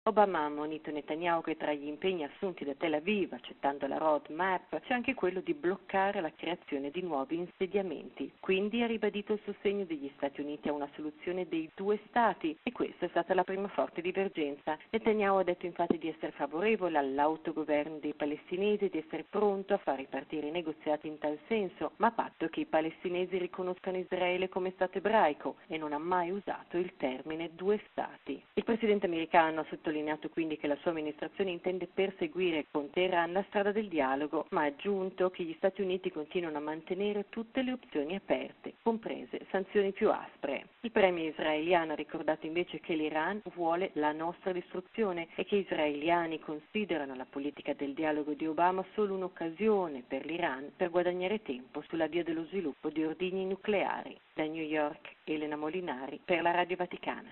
Forti divergenze tra Stati Uniti e Israele sulla crisi mediorientale. Il presidente Barack Obama ha ricevuto ieri alla Casa Bianca il neo premier israeliano, Benyamin Netanyahu e i due leader hanno evidenziato posizioni differenti sulla possibile creazione di uno Stato palestinese, accanto a quello israeliano, e sui rapporti con l’Iran. Da New York